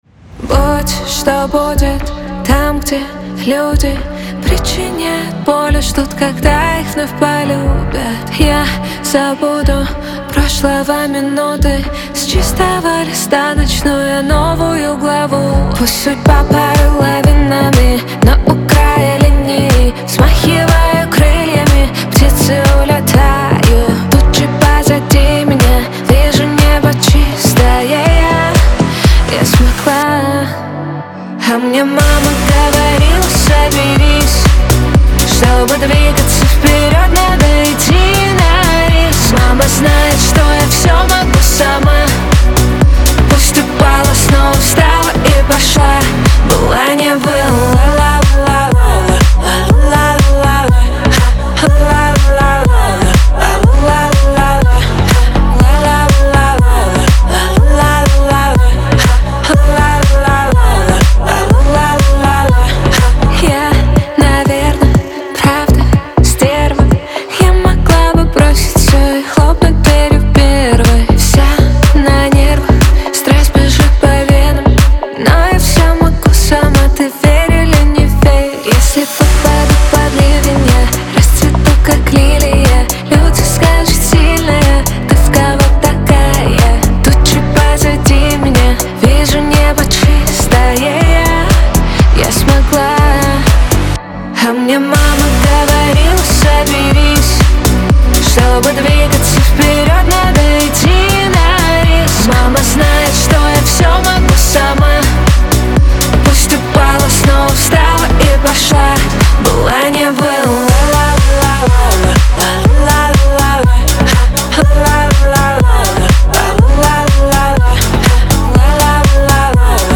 dance , pop , танцы
Веселая музыка